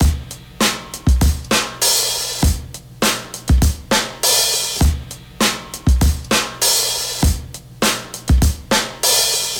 • 100 Bpm Drum Groove D Key.wav
Free breakbeat sample - kick tuned to the D note. Loudest frequency: 3588Hz
100-bpm-drum-groove-d-key-JXL.wav